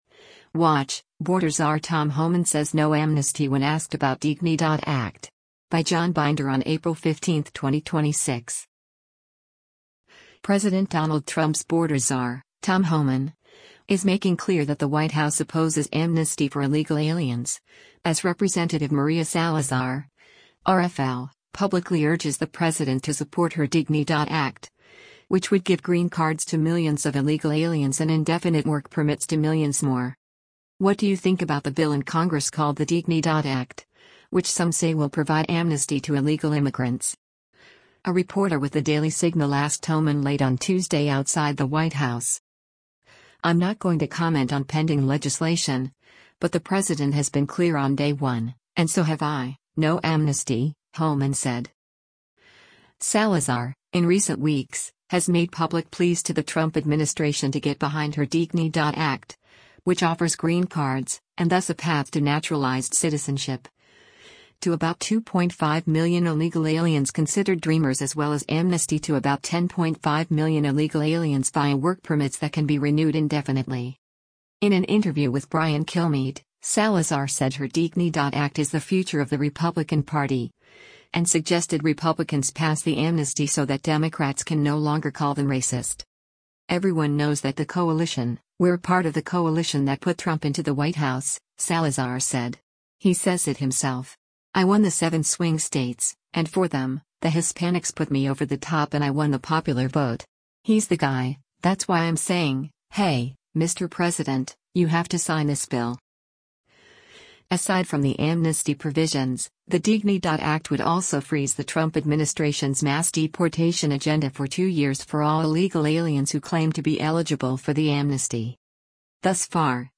“What do you think about the bill in Congress called the DIGNIDAD Act, which some say will provide amnesty to illegal immigrants?” a reporter with the Daily Signal asked Homan late on Tuesday outside the White House.